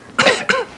Cough Sound Effect
Download a high-quality cough sound effect.
cough-4.mp3